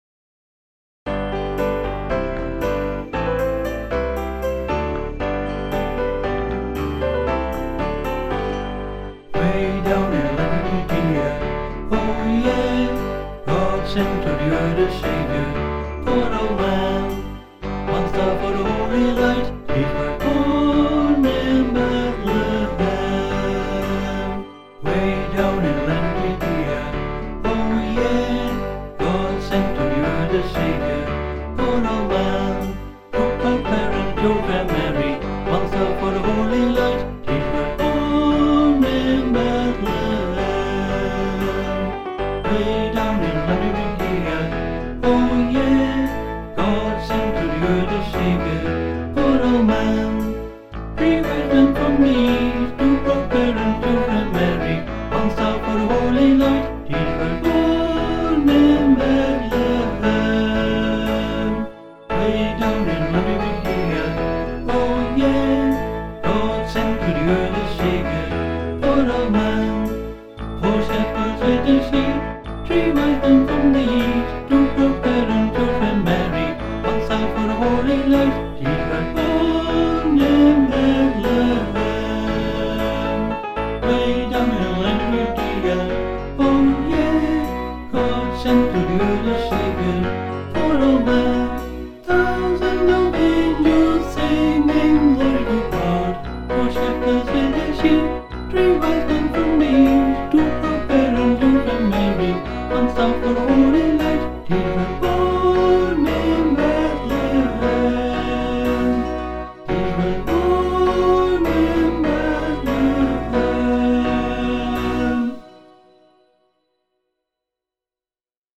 (a Christmas carol written in the musical style